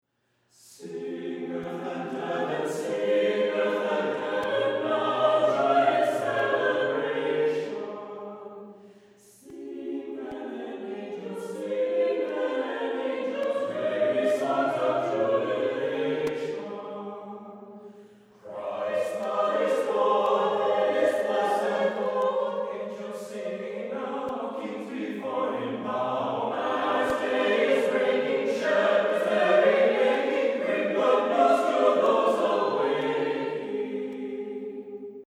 Tonality: A flat major